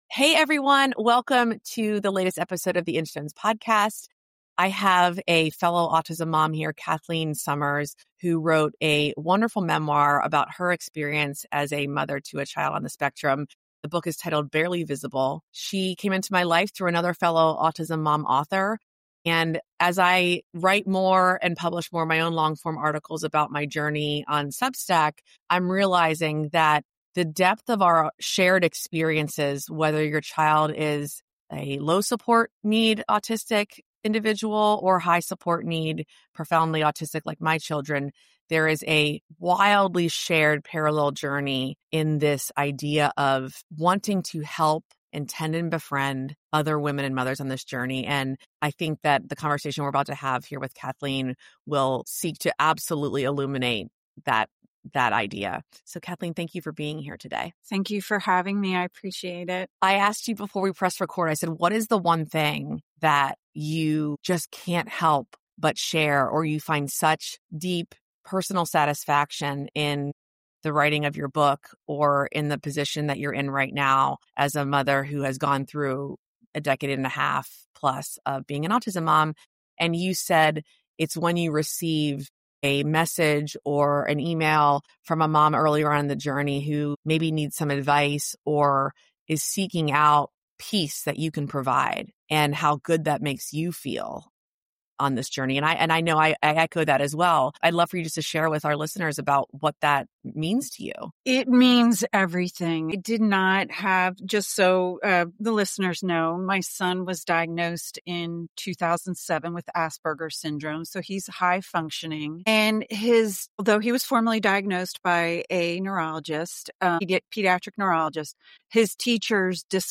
This dialogue invites autism parents to trade anxiety for awareness, performance for presence, and pressure for patience.